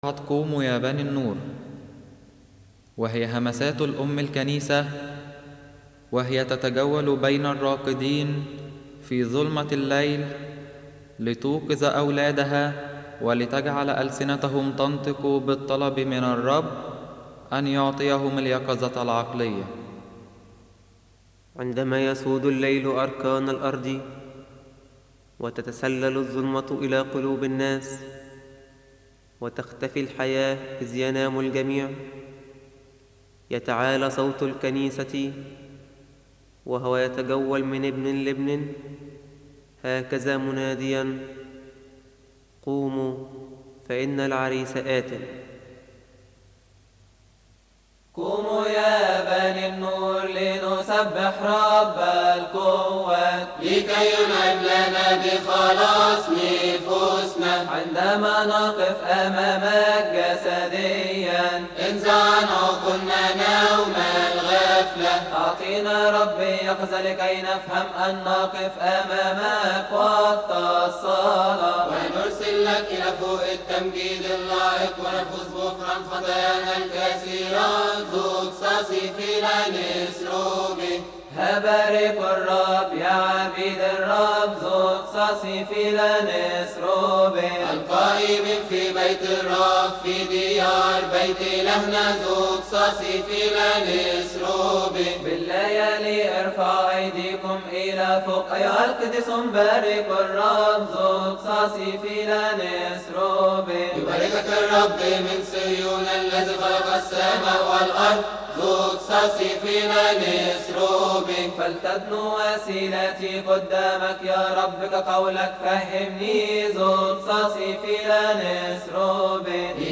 استماع وتحميل لحن قوموا يا بنى النور من مناسبة keahk